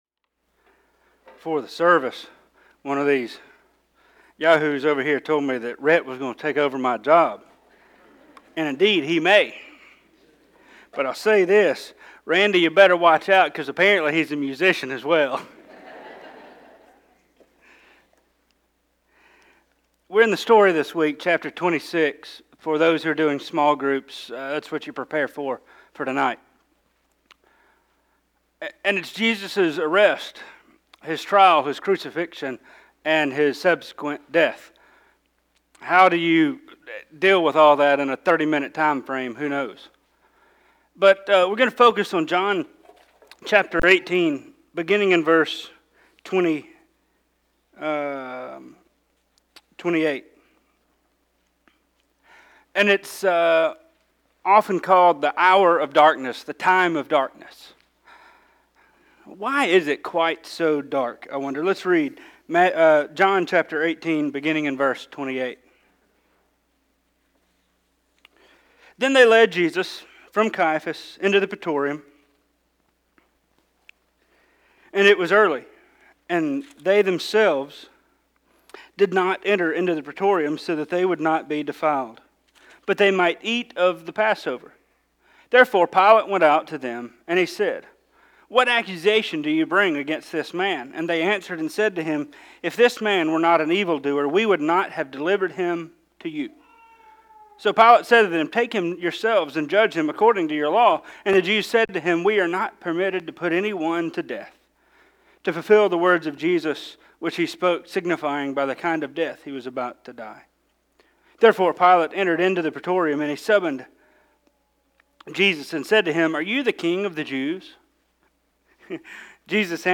Sermons | OCILLA BAPTIST CHURCH